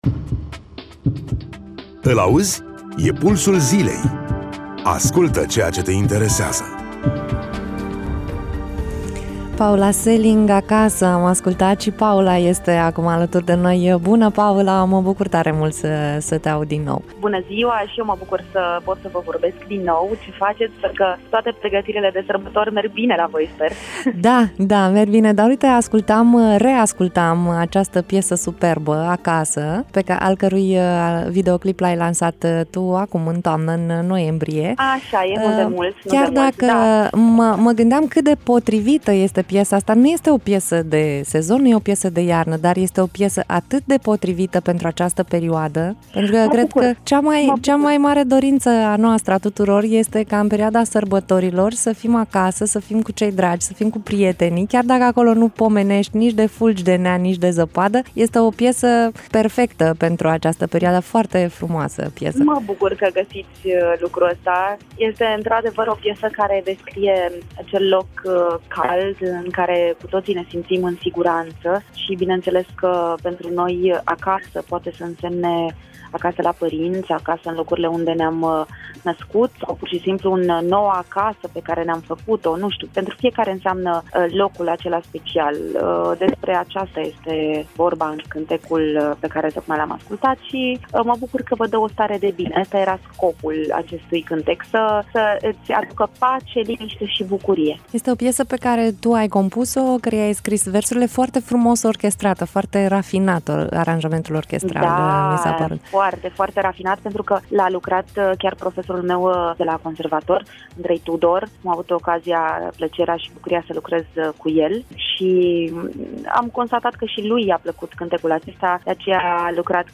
Interviu-Paula-Seling.mp3